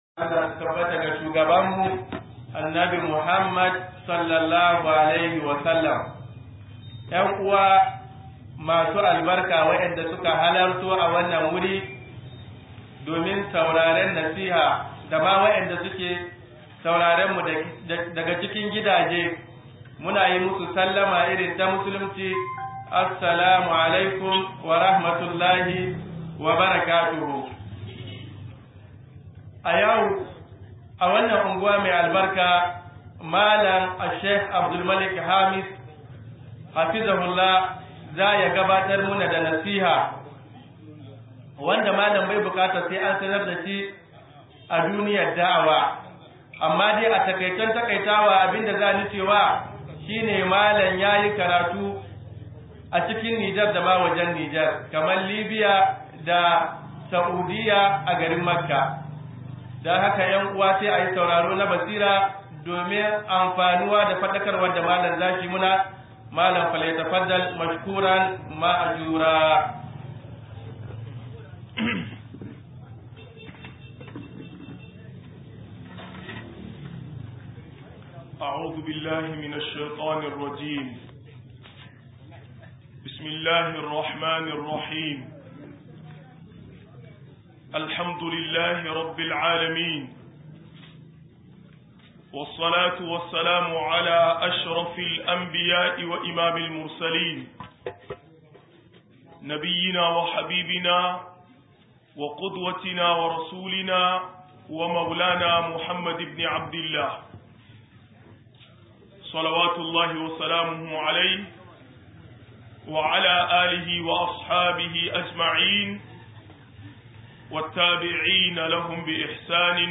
156-Godema Ni imomin Allah - MUHADARA